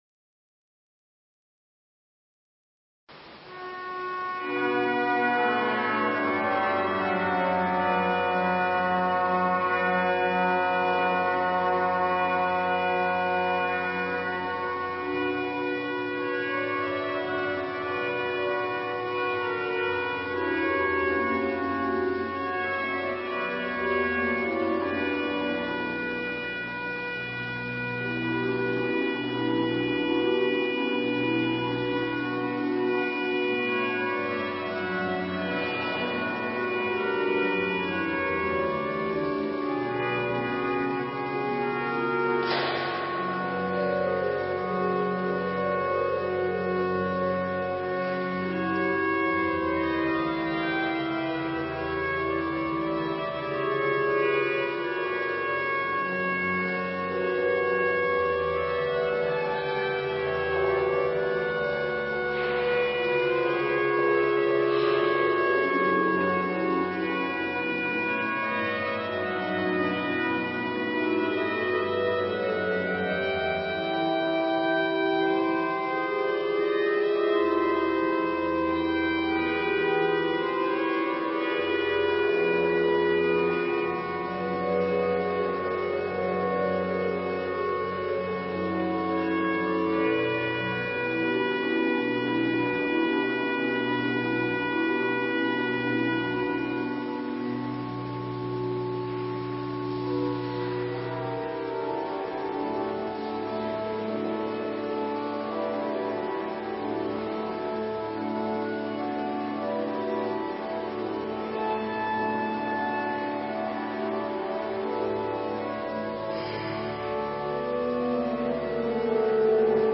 Lezingen
Eucharistieviering beluisteren vanuit de Willibrorduskerk te Wassenaar (MP3)